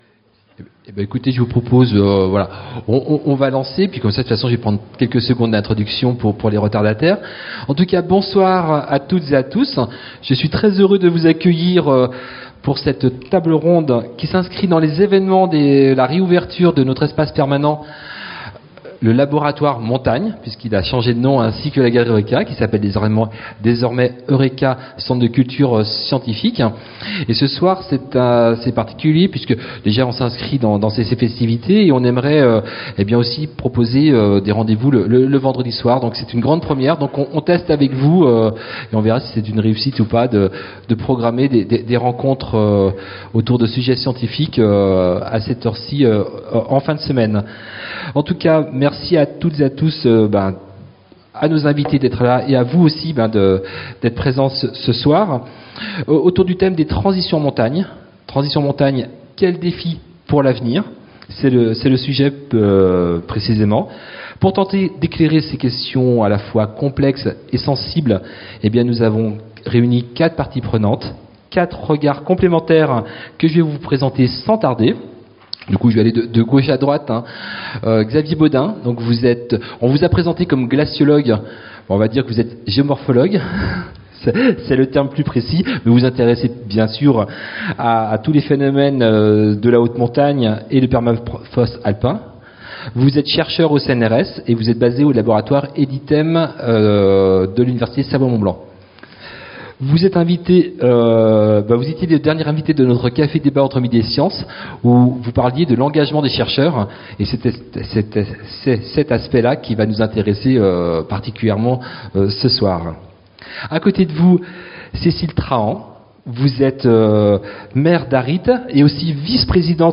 A l’occasion de la réouverture du nouvel espace permanent d'Eurêka, le "Labo Montagne", dédié aux sciences et à la montagne, scientifiques et élus sont invités à croiser leurs regards autour d’un enjeu majeur : les transitions en montagne.
Cette table ronde ouvre un moment de dialogue entre science, territoire et citoyenneté et marque un nouveau chapitre pour Eurêka, le Centre de culture scientifique de la Ville de Chambéry.